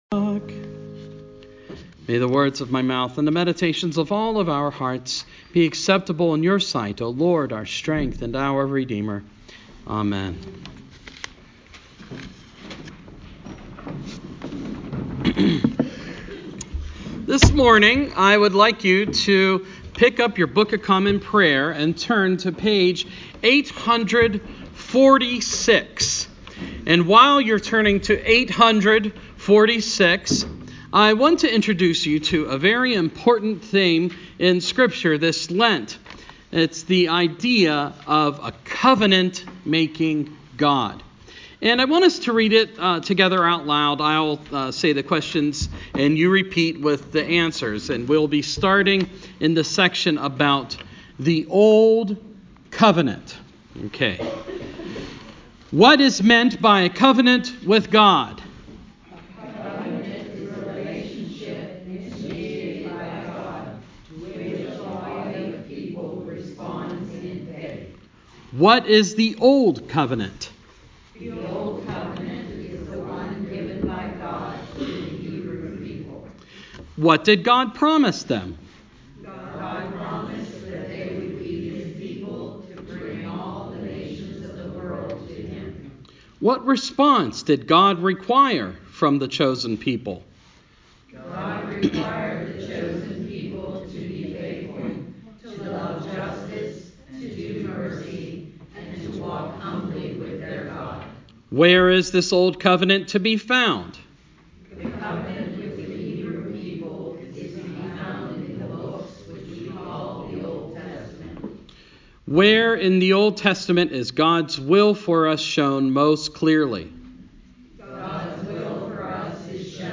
Sermon – First Sunday in Lent